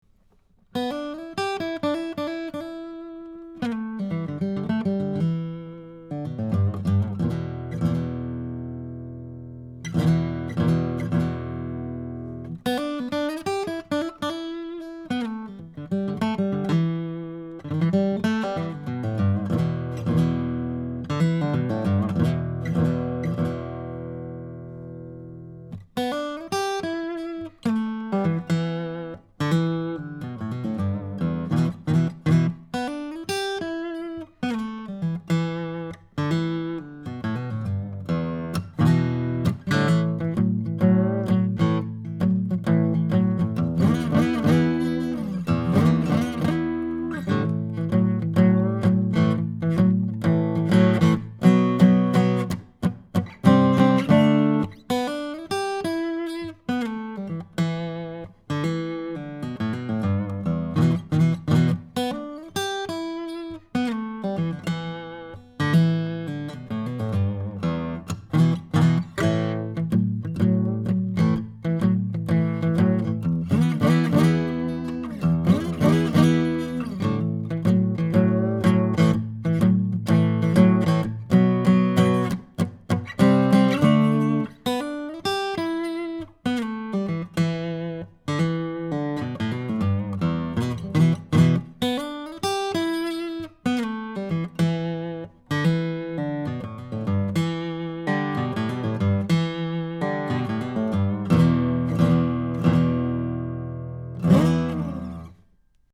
Here are 2 dozen quick, 1-take MP3s using this U87 in a large room -- running on battery power -- into a Sony PCM D1 flash recorder (which does not have P48 Phantom Power), with MP3s made from Logic. These tracks are just straight signal with no additional EQ, compresson or effects:
SANTA CRUZ OM/PW:
Cardioid:
OMPWU87cD1Boogie.mp3